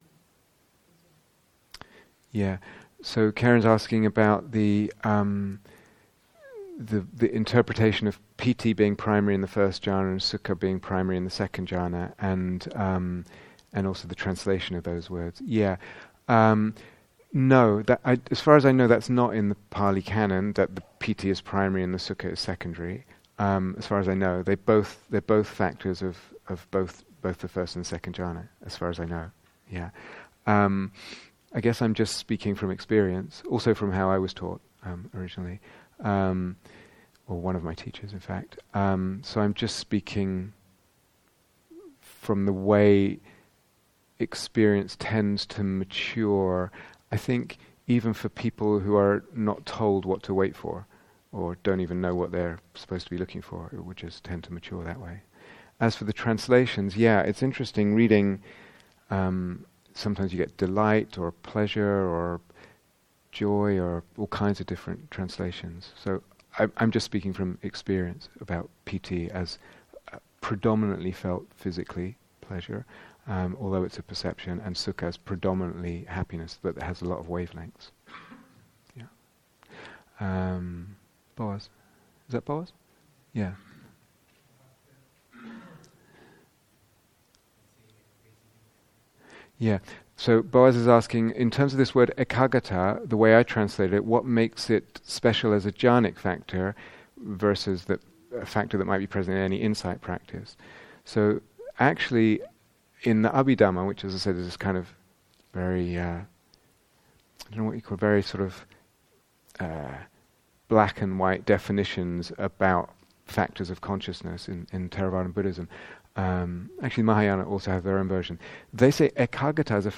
Q & A